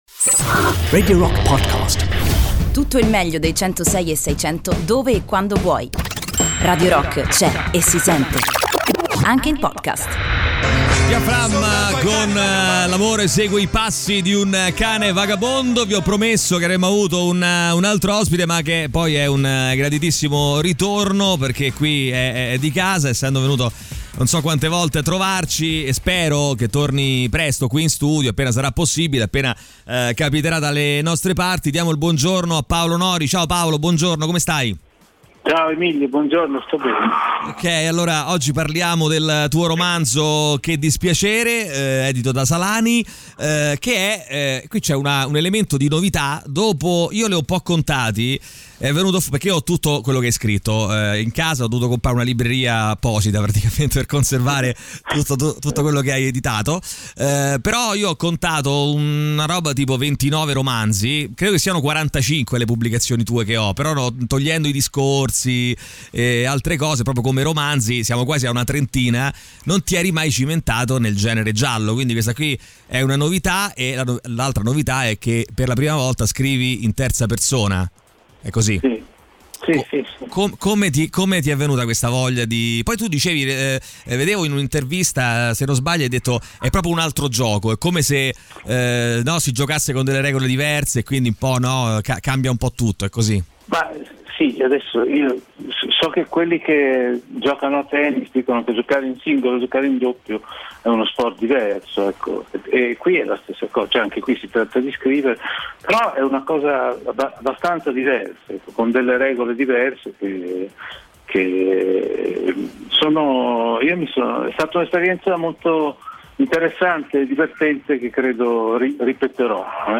Intervista "Paolo Nori" (24-07-20)
in collegamento telefonico con Paolo Nori durante il THE ROCK SHOW